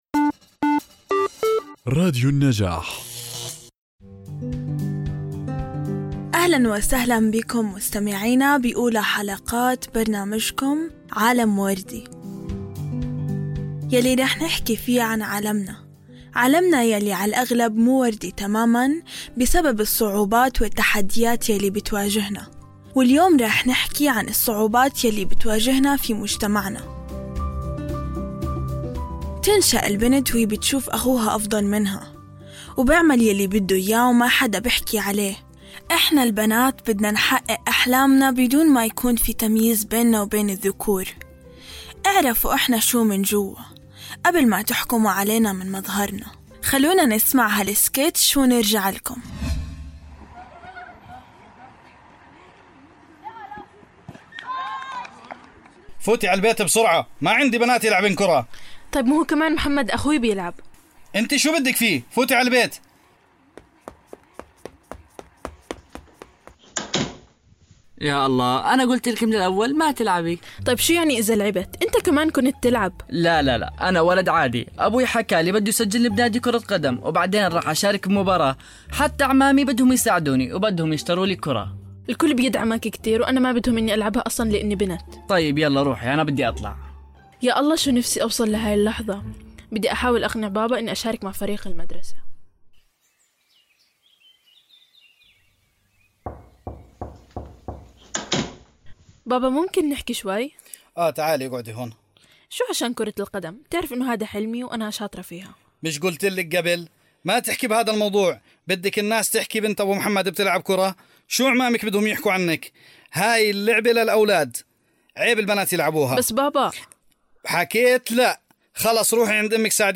من خلال سكيتش تمثيلي مبتكر ومؤثر، تقدم الحلقة صورة حية للتحديات التي تواجهها البنات بسبب الجندرية المحددة اجتماعيا، يشتمل السكيتش على أحداث وقصص حقيقية تم تجسيدها بطريقة تعكس الواقع الذي يعيشه الكثير من الفتيات والنساء، وتبرز الفجوة الكبيرة بين الجنسين في مجتمعاتنا.
عالم وردي هو برنامج مميز يقدمه مجموعة من اليافعات المشاركات ضمن مشروع نبادر في مركز حكاية لتنمية المجتمع المدني، يتميز هذا البودكاست بتضمينه سكيتشات تمثيلية درامية تعكس تجارب الفتيات واليافعات في مواجهة قضايا مثل التمييز بين الذكور والإناث، عدم الثقة بالبنات، تأثير السوشل ميديا على حياتهن، العنف ضد البنات والعصبية.